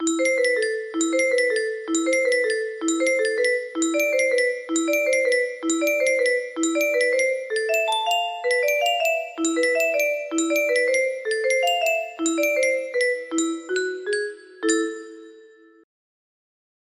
Beethoven - Tempest music box melody
Beethoven Sonata 17, movement 3 - Tempest, simplified version.